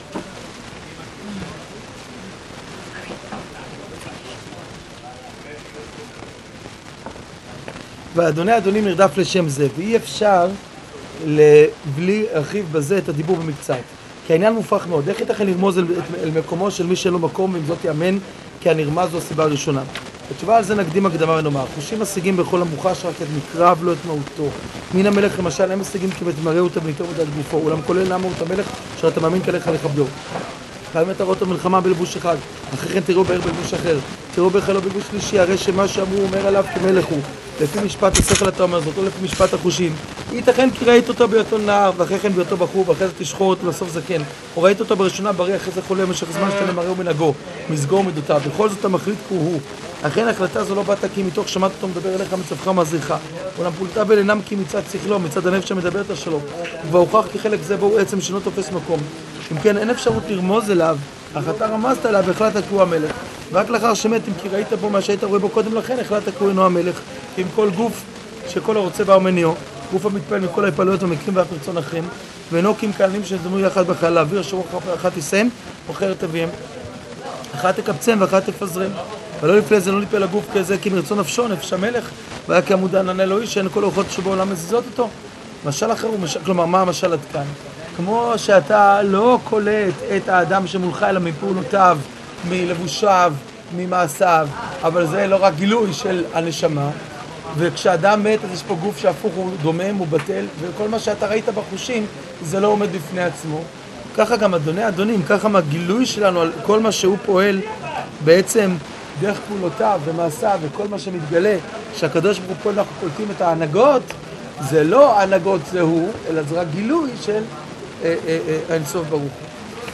שיעור ואי אפשר